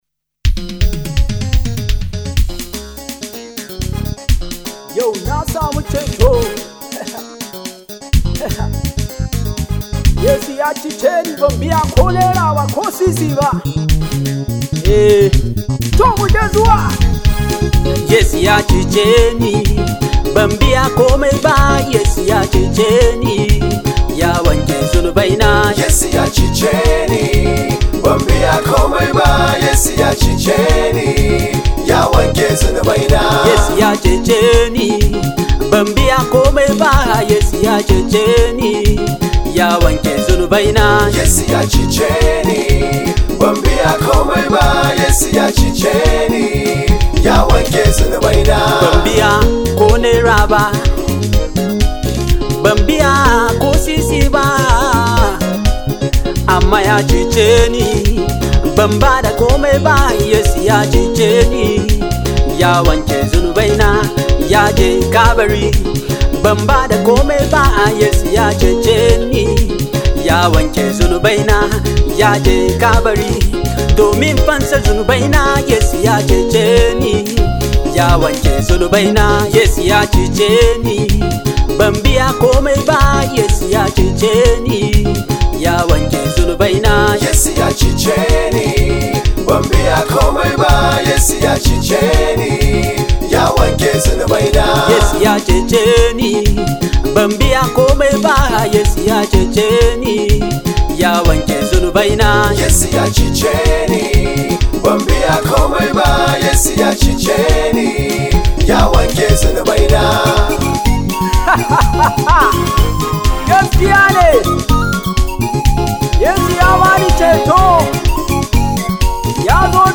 Nigerian gospel music
phenomenal hausa praise song